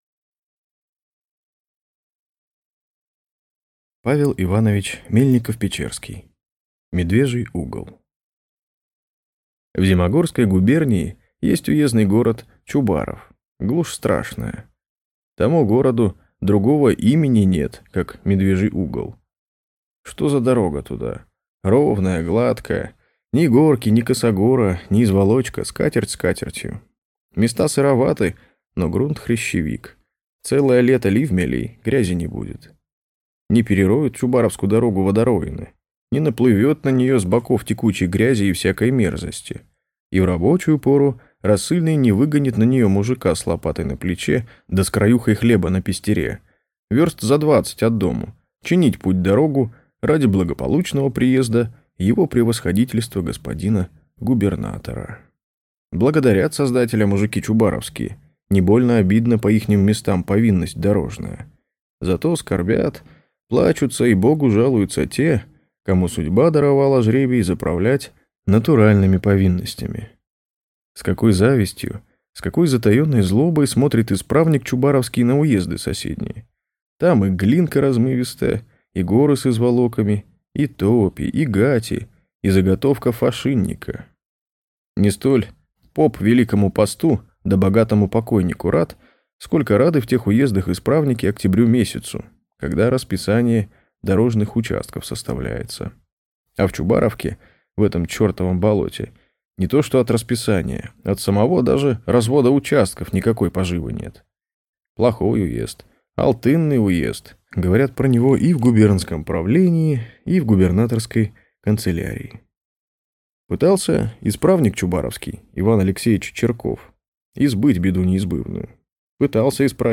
Аудиокнига Медвежий Угол | Библиотека аудиокниг